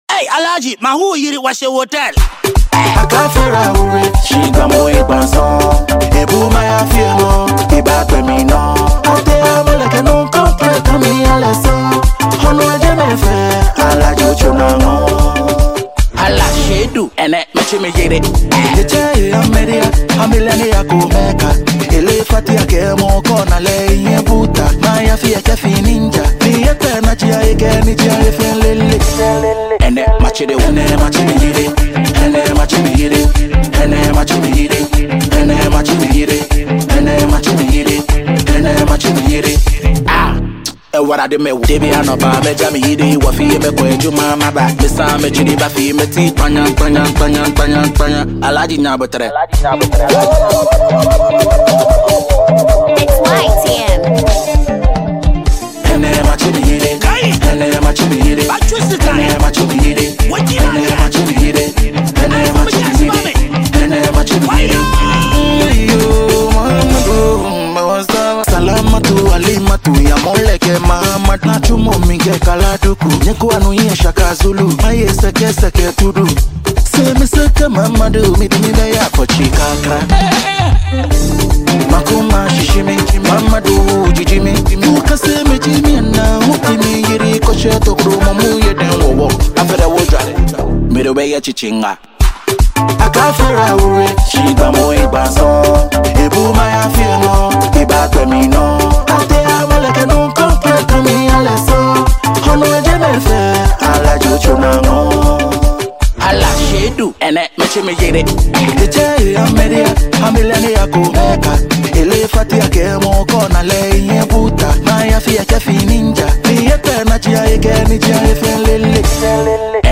mixed and mastered
well-produced song